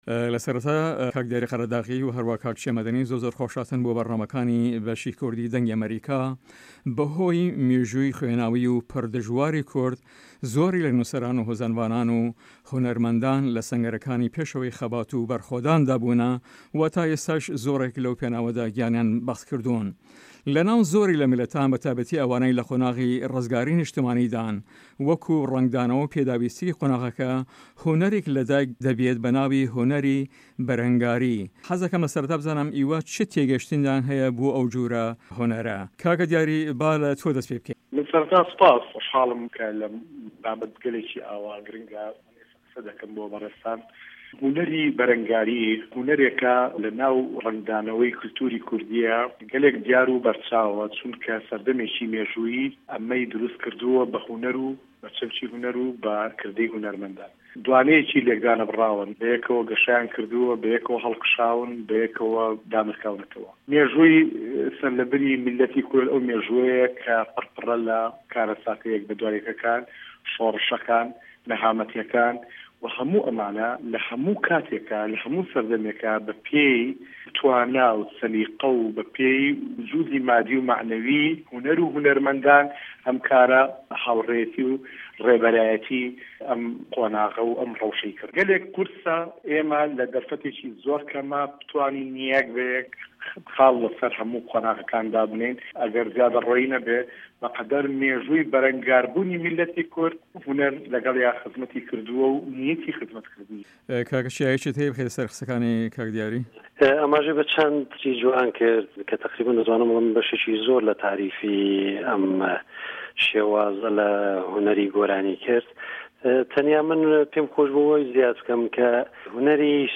مێزگردی هه‌فته‌: موزیکی به‌ره‌نگاری